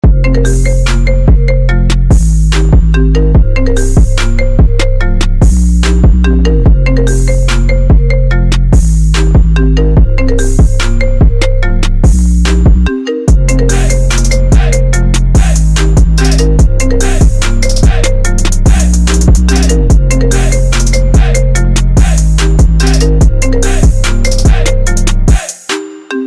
I Phone Ringtones